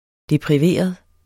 Udtale [ depʁiˈveˀʌð ]